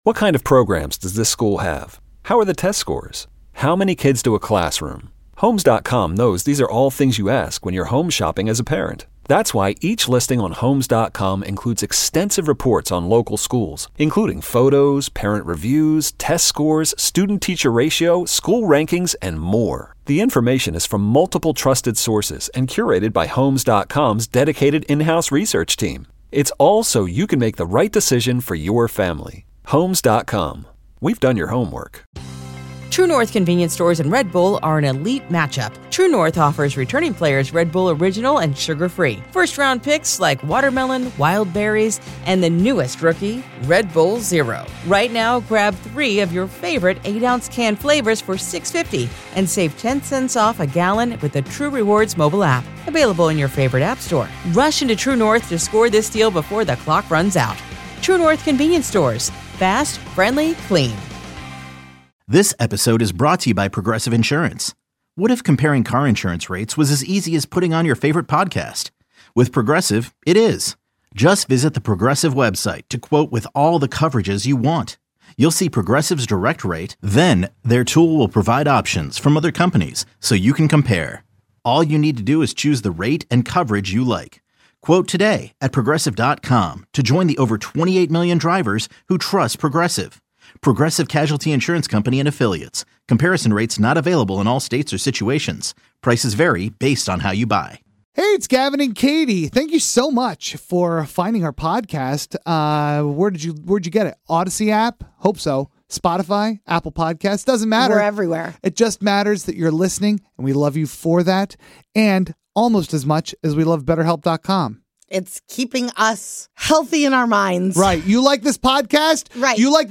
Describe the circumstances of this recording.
The world's okayest morning radio show offers you the entire broadcast from today with none of the music and limited commercials.